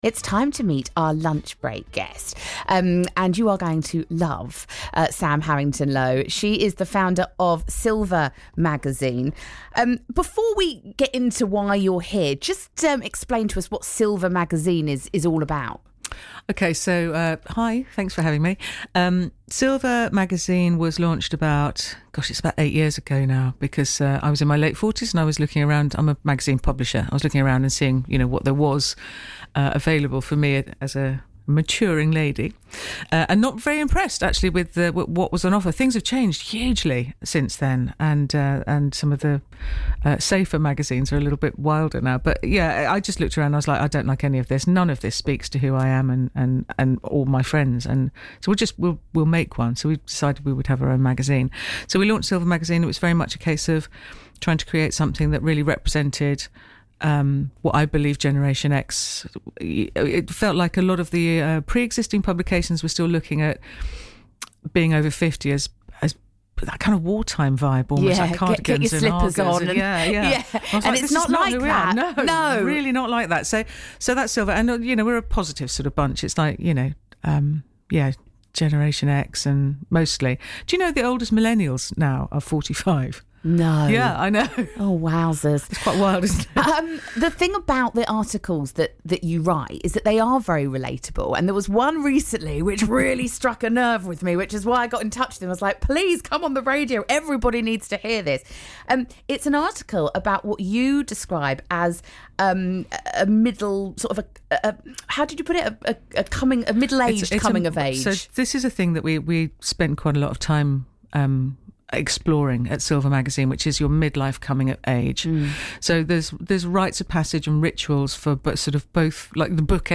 BBC radio interview